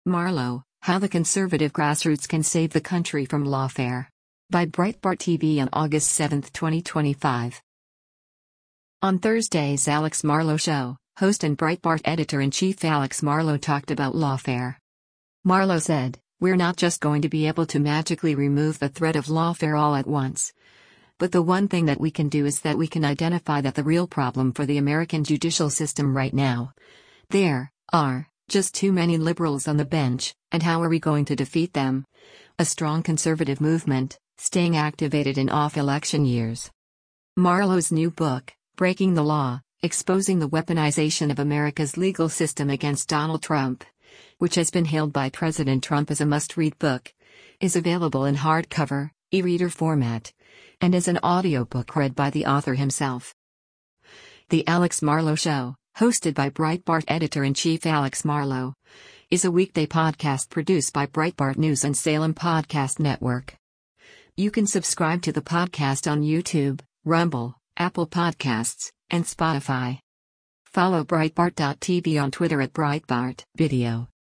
On Thursday’s “Alex Marlow Show,” host and Breitbart Editor-in-Chief Alex Marlow talked about lawfare.